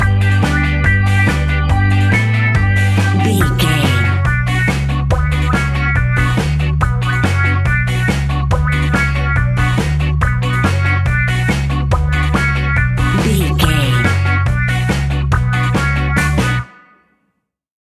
Aeolian/Minor
reggae
laid back
chilled
off beat
drums
skank guitar
hammond organ
percussion
horns